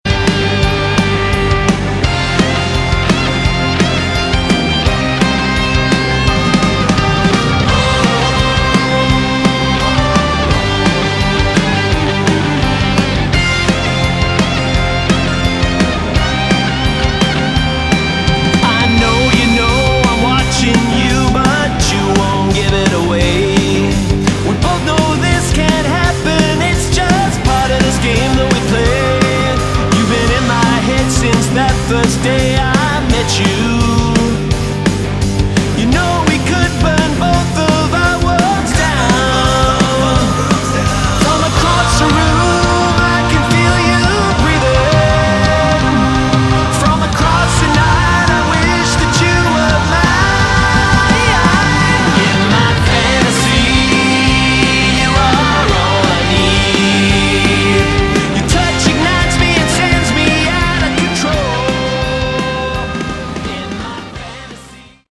Category: Melodic Rock
vocals
bass
guitars
keyboards
drums